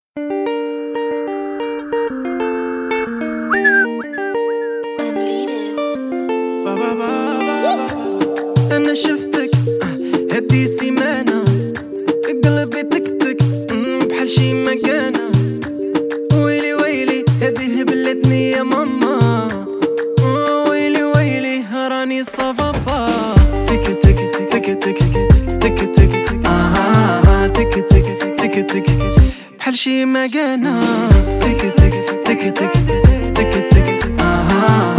Жанр: Поп
# Arabic Pop